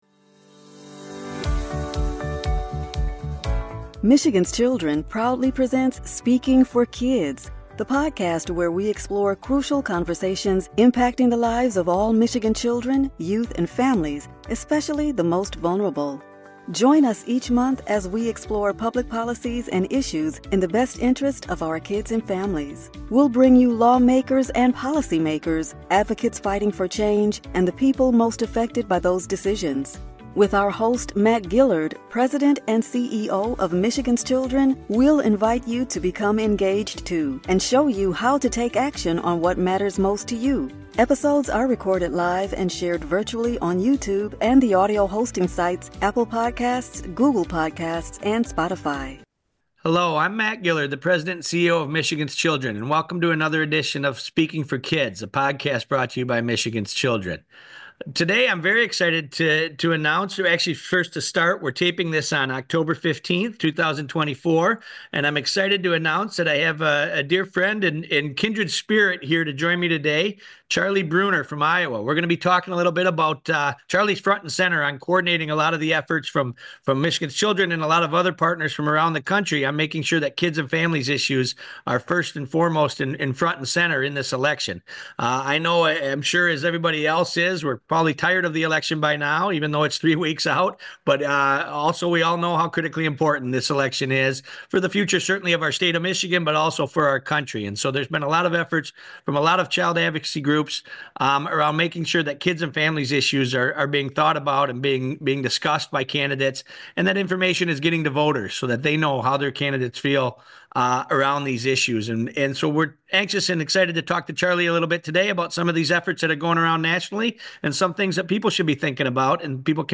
Each month, Speaking for Kids the podcast will bring you a variety of crucial conversations with people making public policy and the voices of the people impacted by those decisions in Michigan.